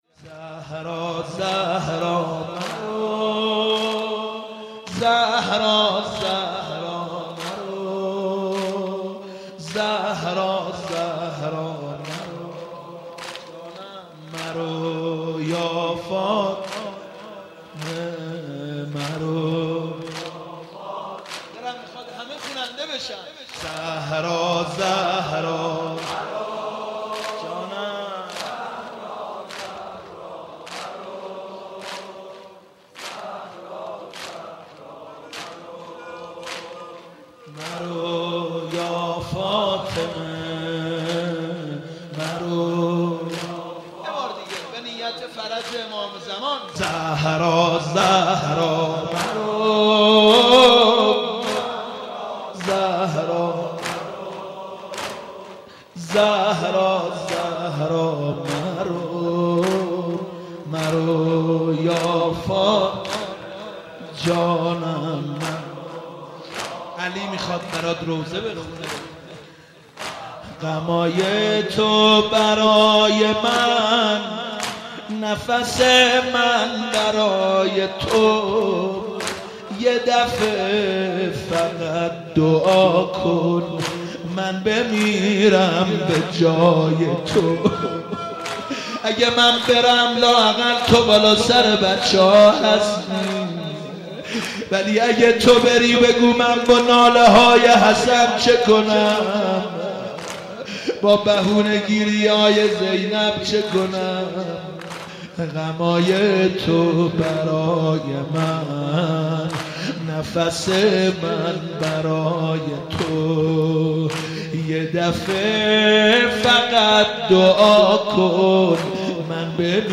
ایام فاطمیه مداحی